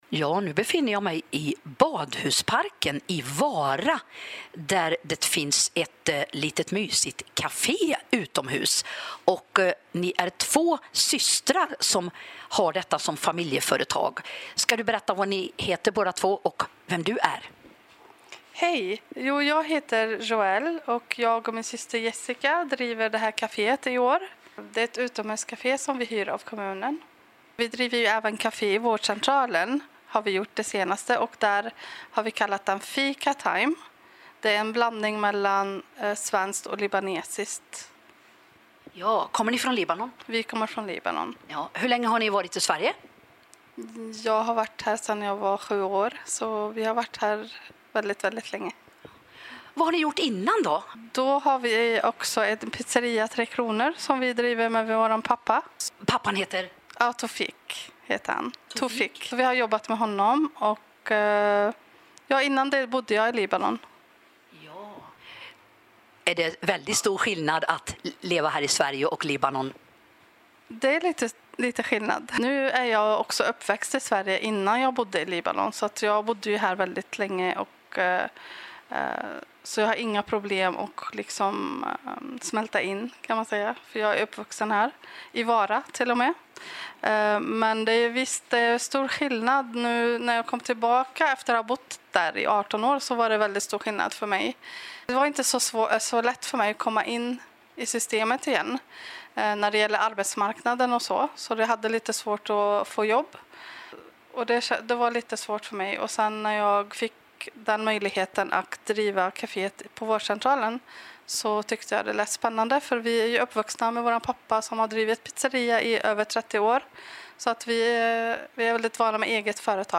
3. Vi står ute vid skylten till fiket i Badhusparken.
Fin intervju.